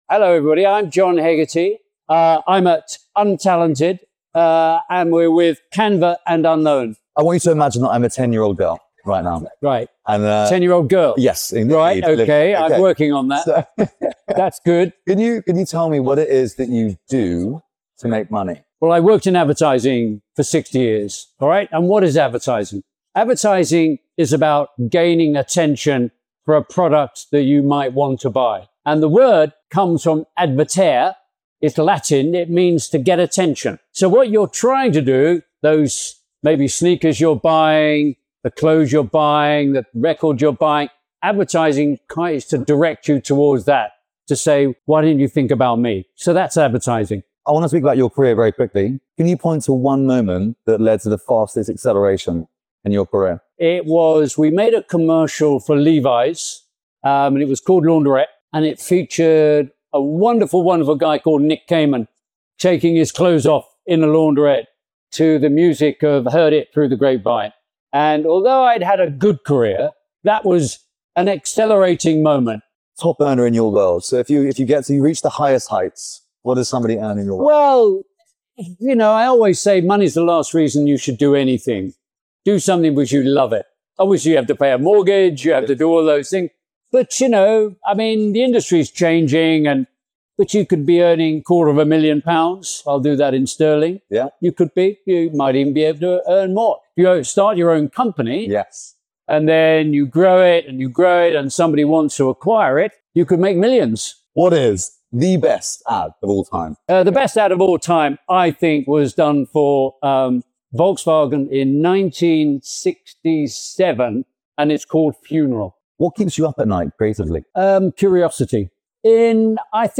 A true highlight from Cannes: sitting down with the one and only Sir John Hegarty - founder of BBH. Decades of experience, distilled into one conversation that’s packed into just 5 minutes.